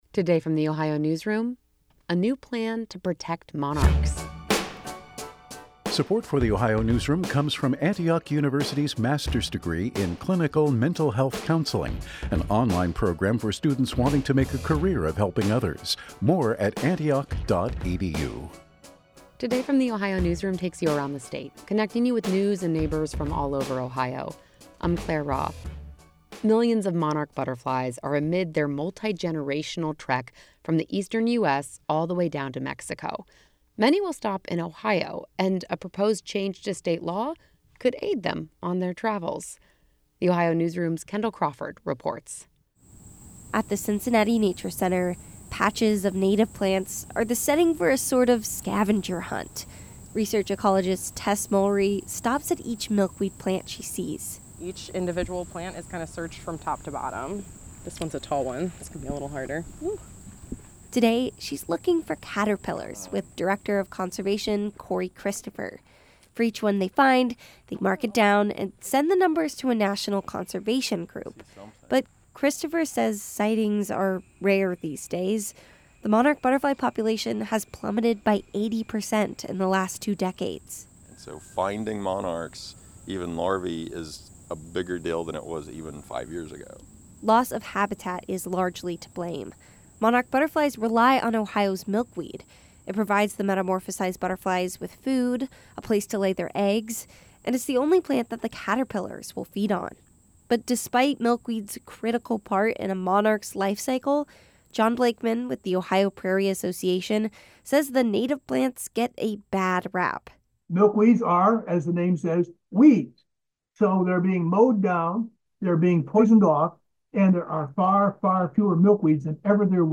At the Cincinnati Nature Center, patches of native plants are the setting for a sort-of scavenger hunt.